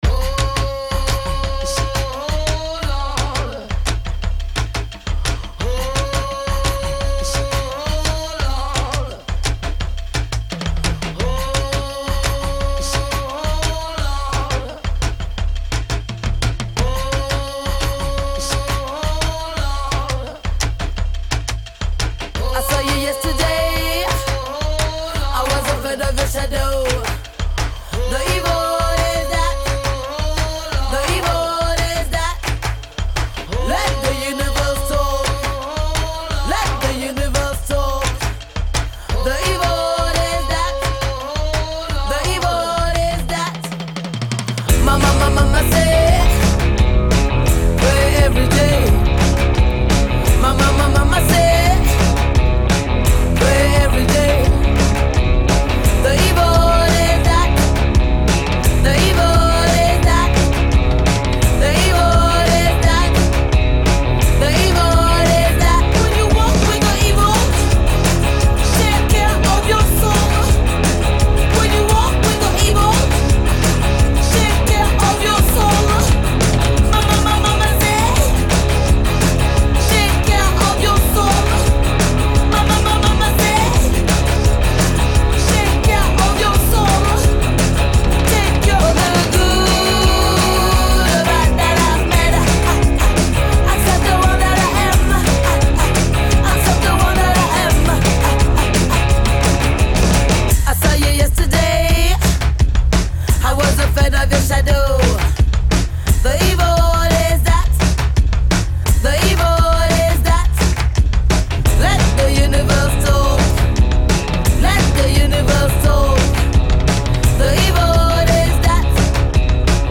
ژانر: پاپ ، دنس